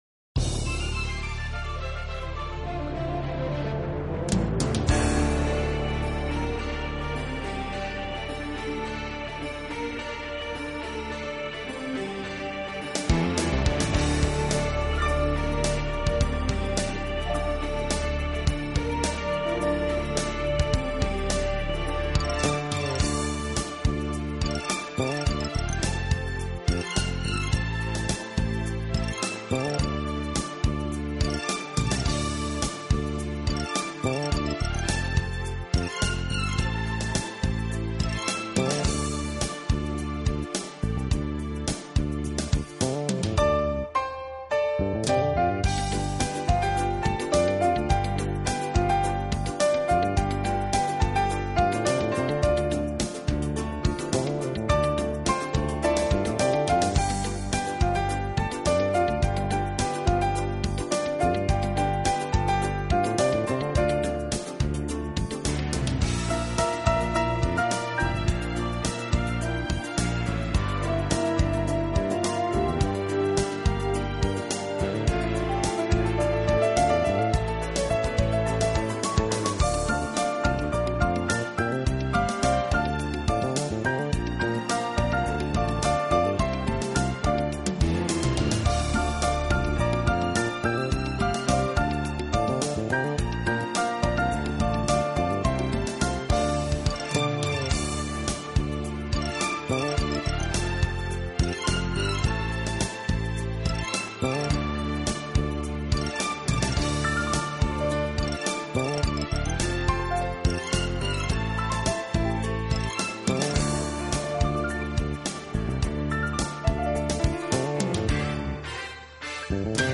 【钢琴】